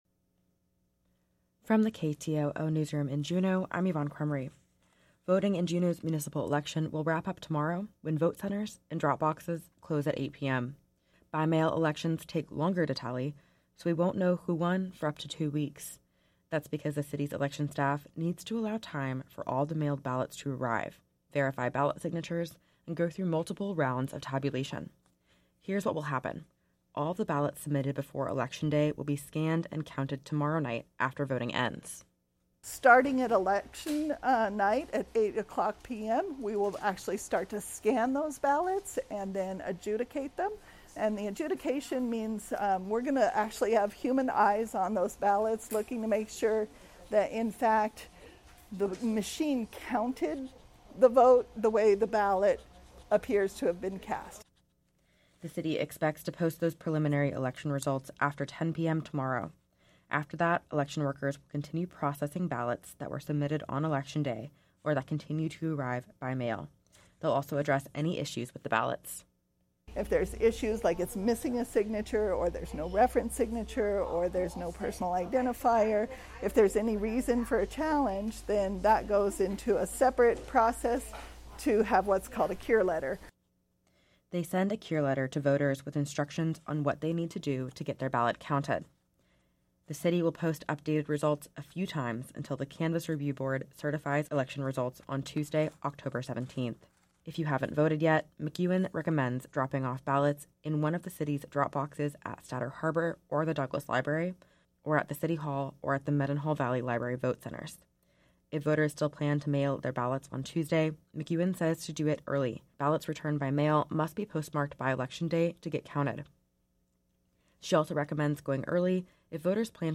Newscast – Monday, Oct. 2, 2023